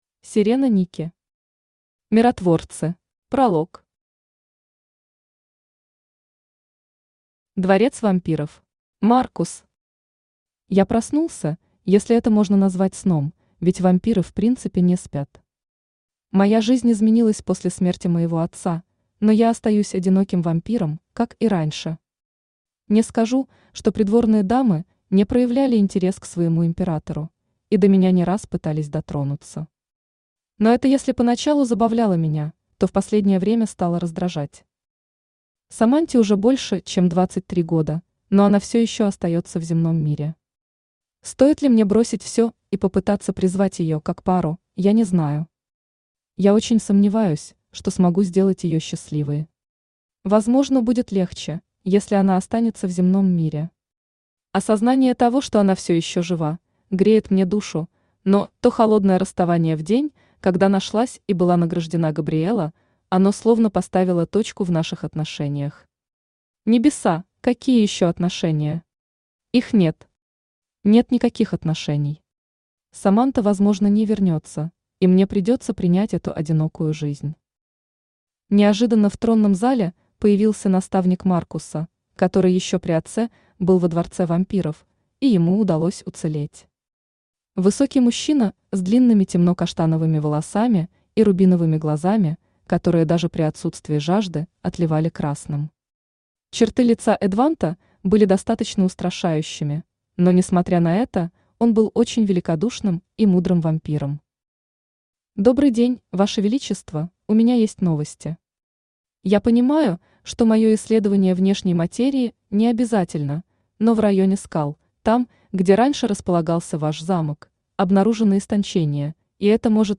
Аудиокнига Миротворцы | Библиотека аудиокниг
Aудиокнига Миротворцы Автор Серена Никки Читает аудиокнигу Авточтец ЛитРес.